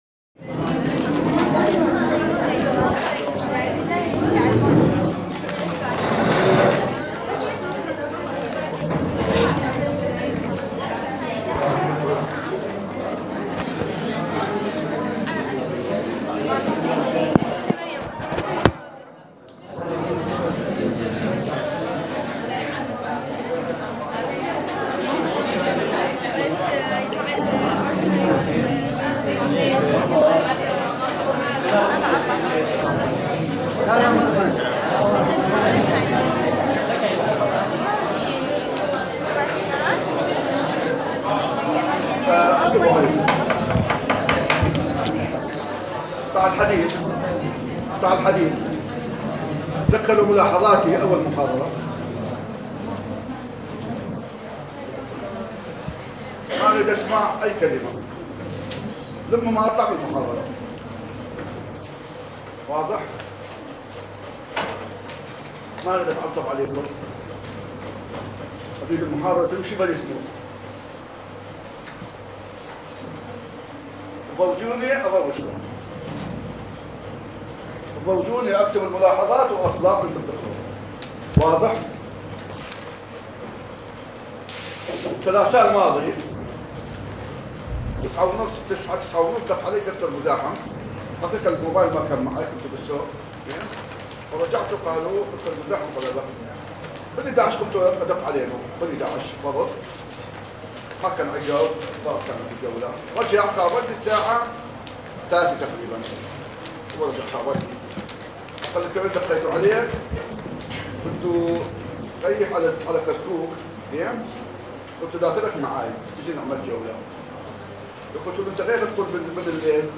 Head and Neck voice recording